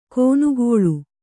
♪ kōnugōḷu